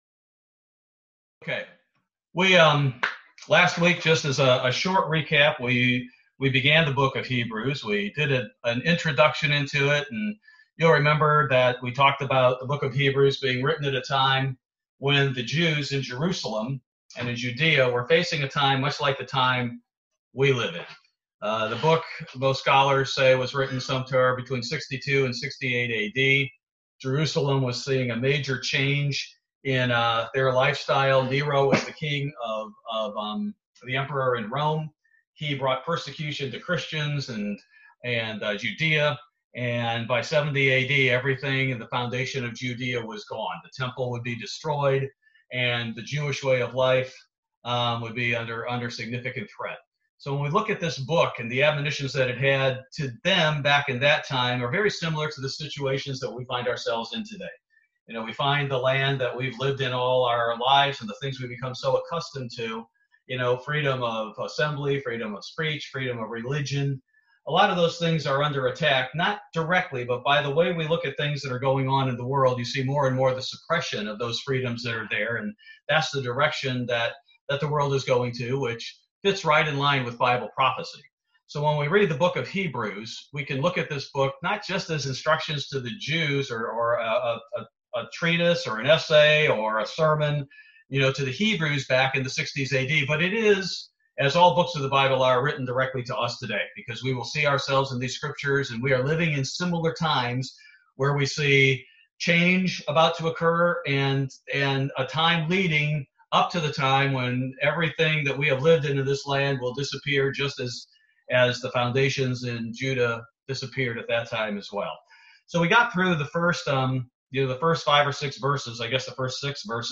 Bible Study: November 4, 2020